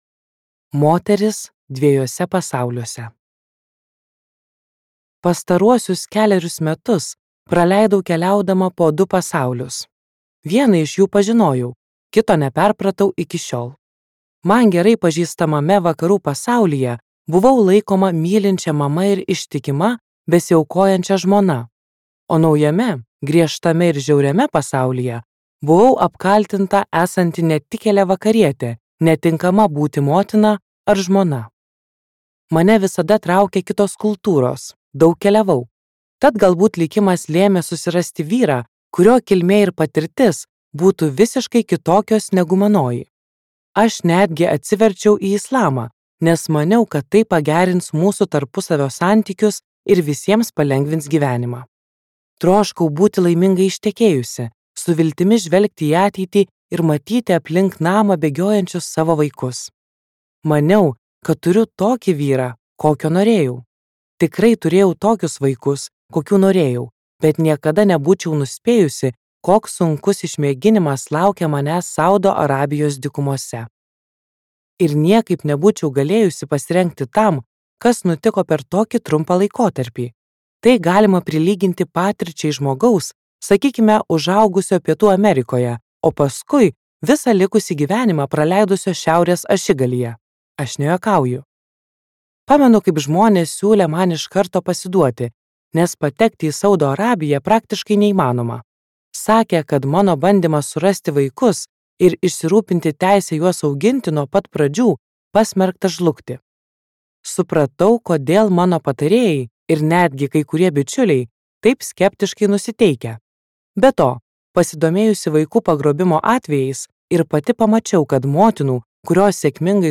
Tikra istorija | Audioknygos | baltos lankos